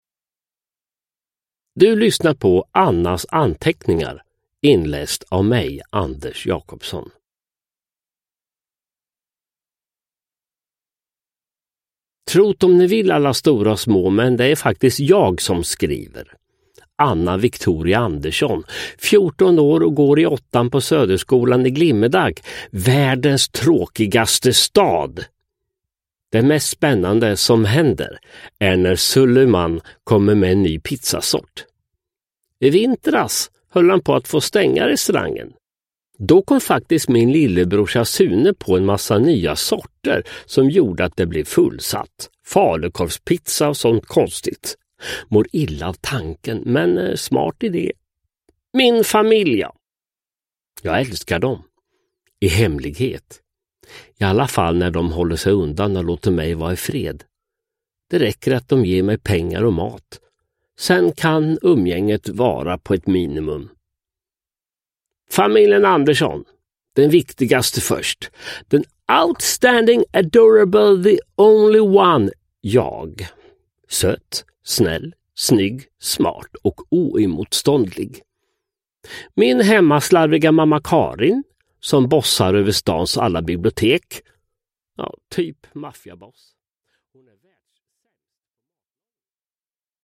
Uppläsare: Sören Olsson, Anders Jacobsson